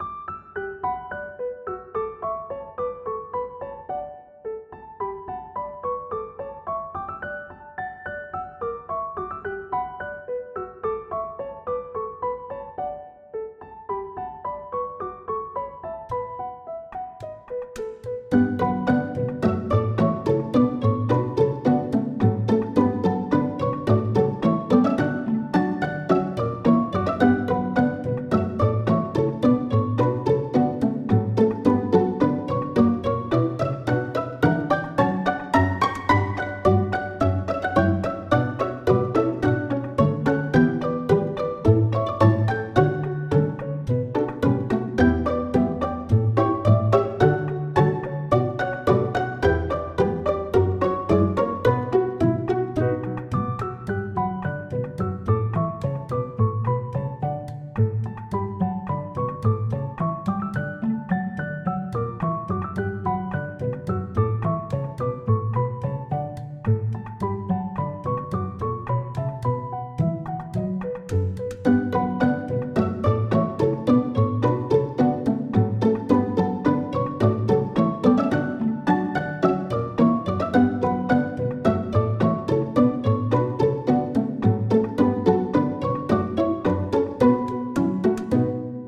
フリーBGM素材- シリアスなシーンに使えそうな曲。前向きな感じ。